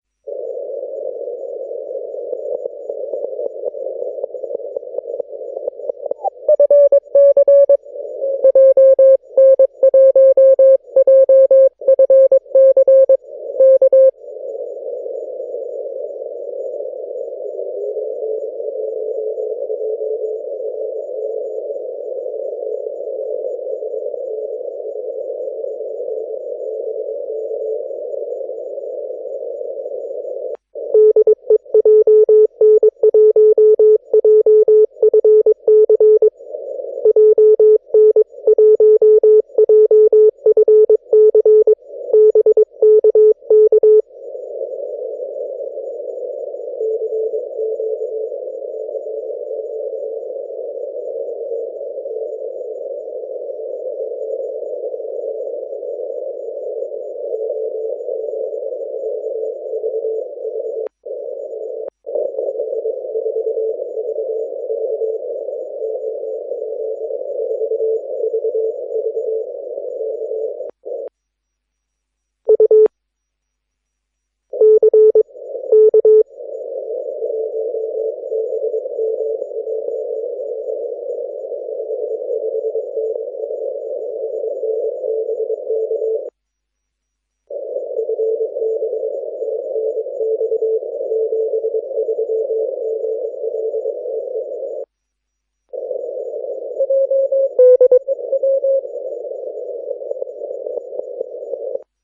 Note that the variation of his CW tone is caused by my RX-VFO manipulation for clarity.